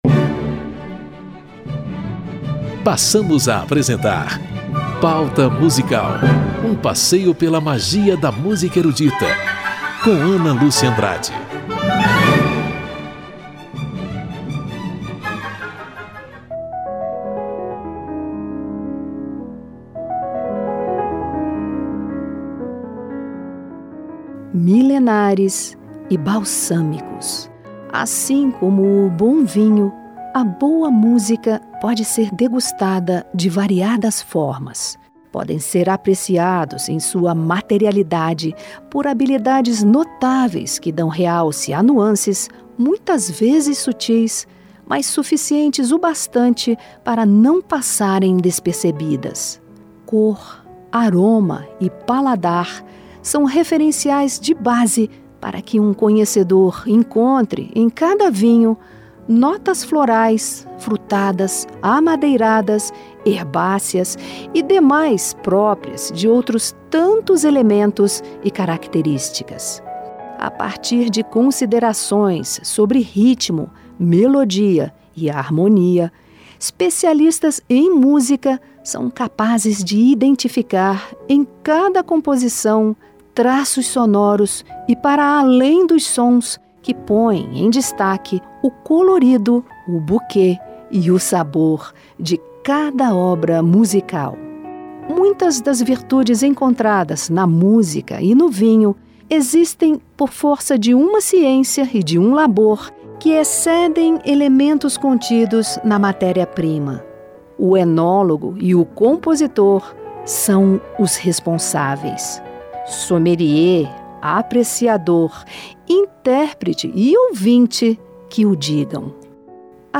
Alquimia e harmonização regada a vinho e boa música nesta edição do programa Pauta Musical. A plasticidade impressionista/simbolista da pedra filosofal de Claude Debussy.